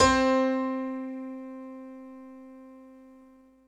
10 SY99 Piano C4.wav